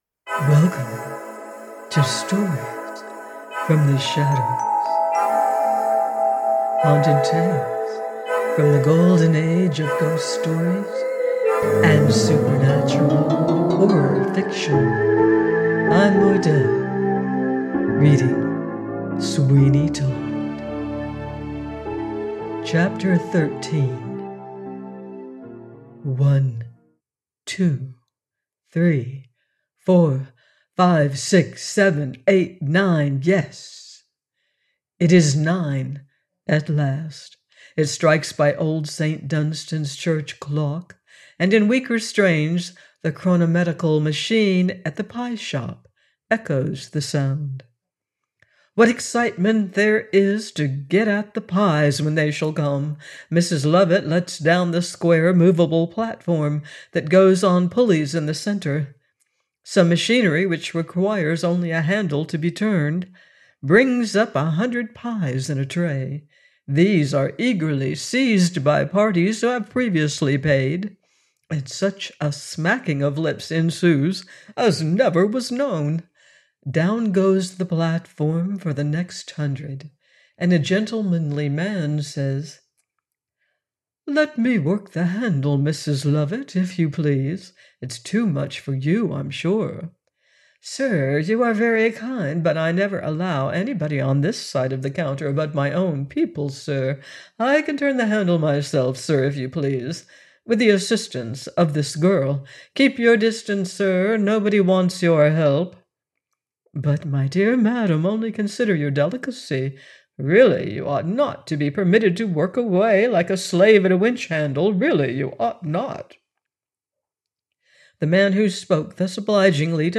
Sweeney Todd – by Anonymous - audiobook